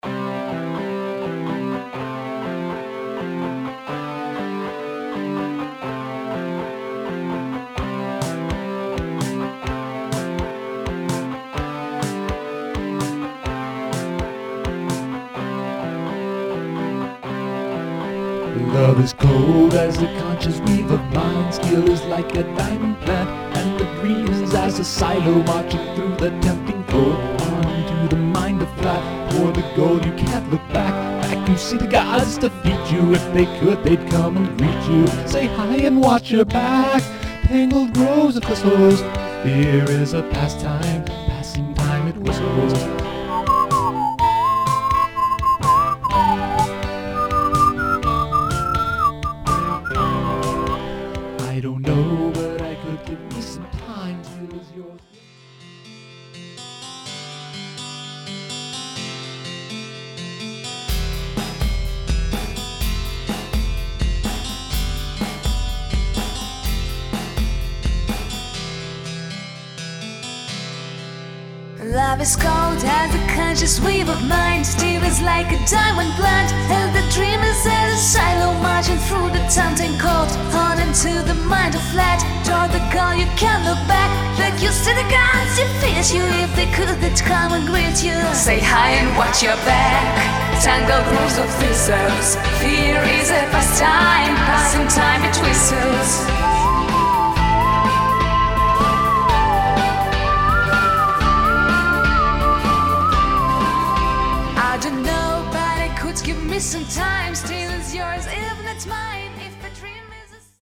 Римейк песни Категория: Написание музыки